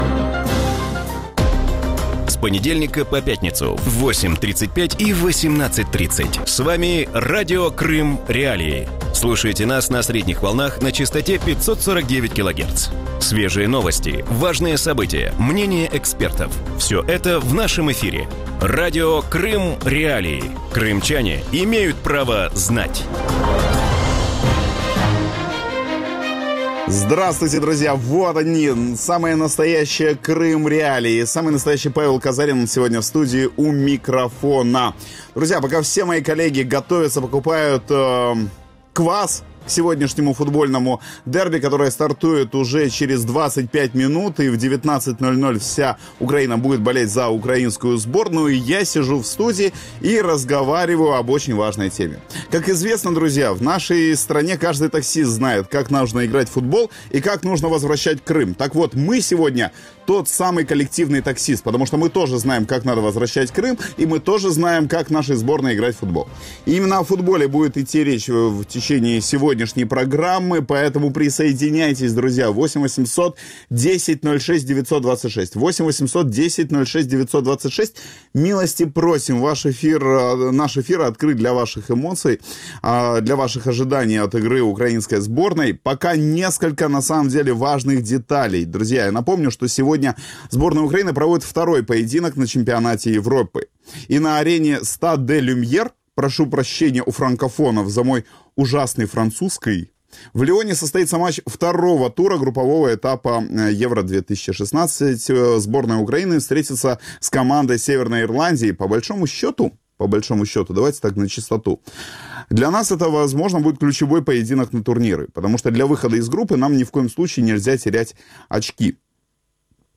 Вечерний эфир Радио Крым.Реалии выходит накануне второго матча сборной Украины в групповом этапе на чемпионате «Евро-2016». В студии обсуждают, как крымским футбольным фанатам удается болеть за спорт без политики.